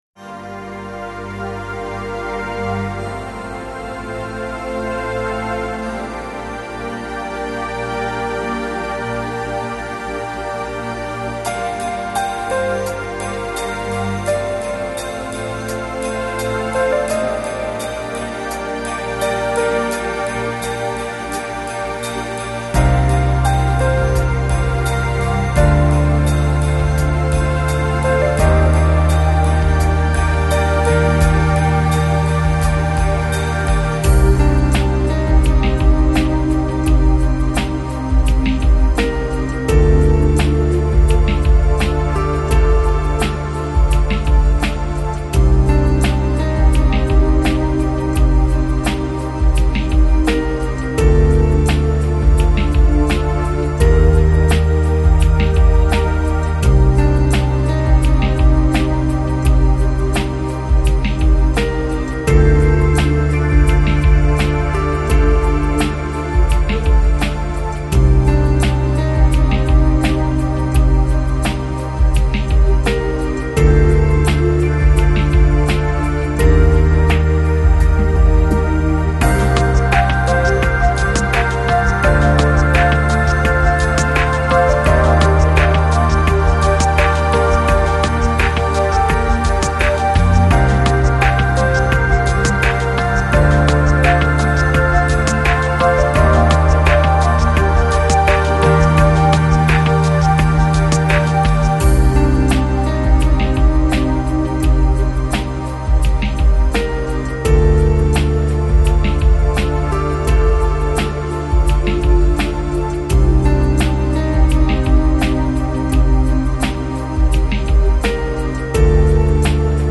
Жанр: Chill Out, Chill House, Downbeat, Lounge